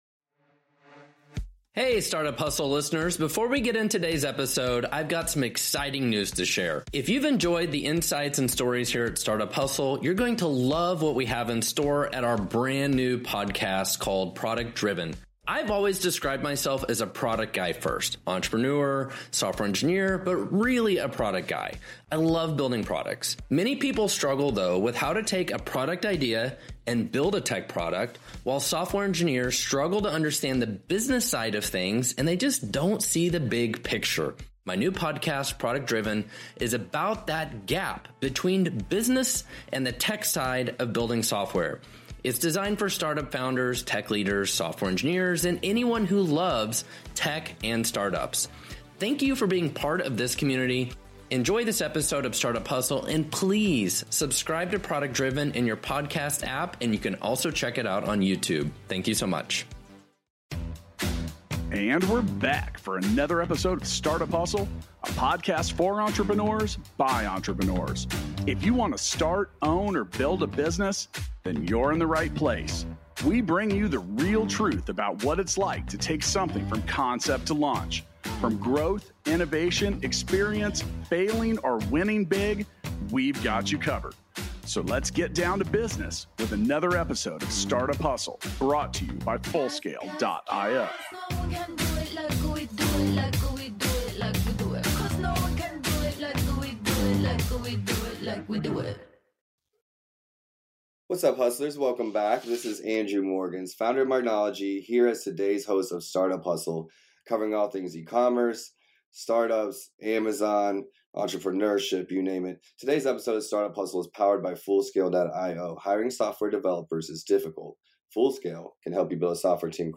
for a conversation about the global language of business. Hear what the future of retail holds using 2d barcodes to manage item catalogs, allowing small businesses to compete in the global market.